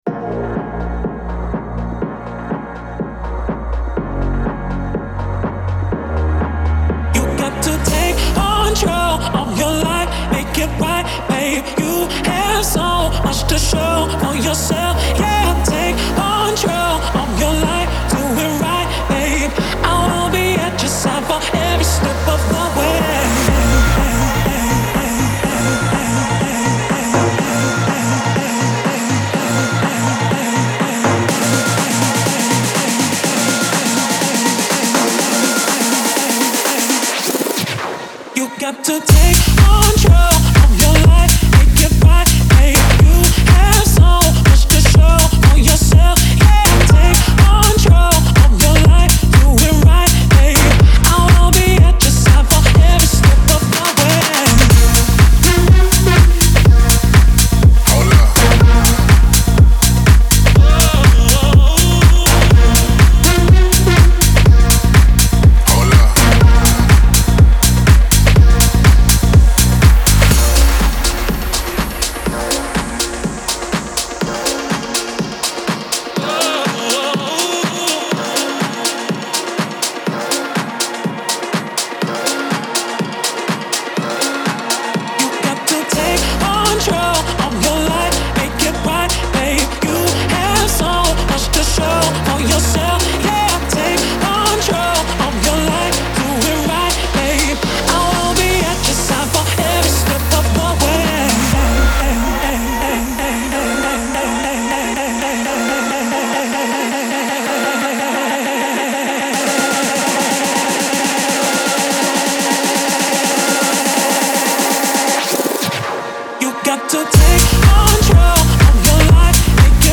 динамичная электронная композиция